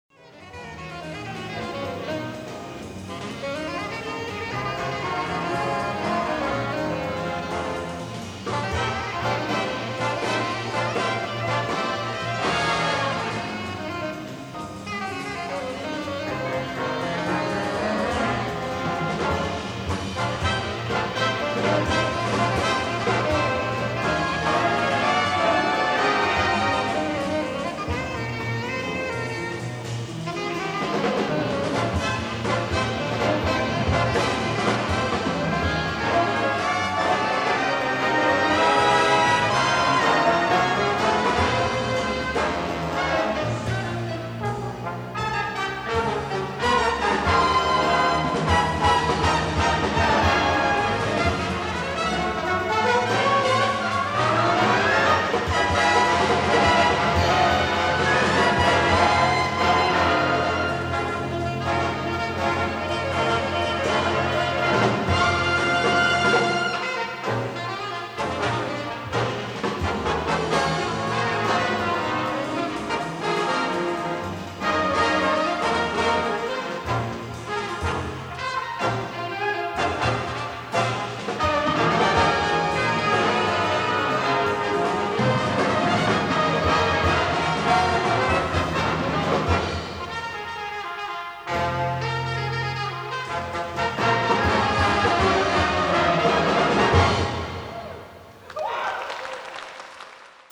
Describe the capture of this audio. From my New England Conservatory senior recital: Note: the piano sounds a bit distorted because the "distortion" switch was accidentally turned on on the piano's portable amplifier (at the recital).